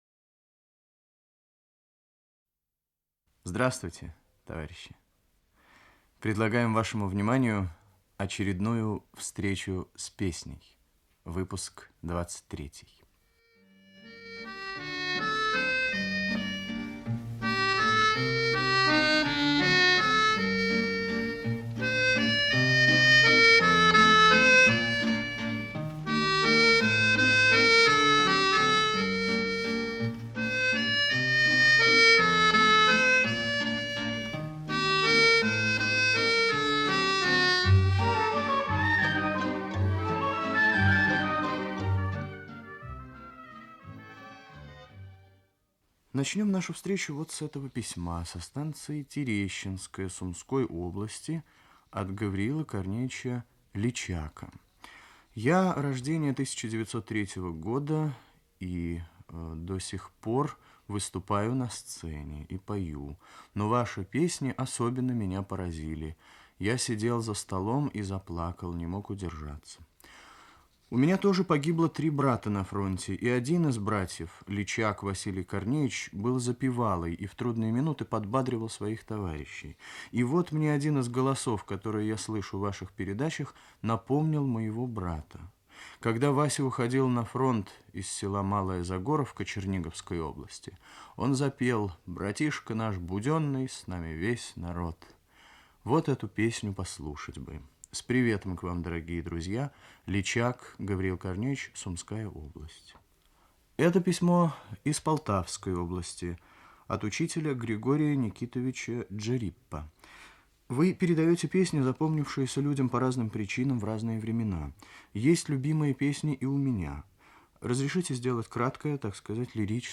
Русский романс